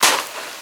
STEPS Sand, Walk 24.wav